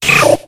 Audio / SE / Cries / CYNDAQUIL.ogg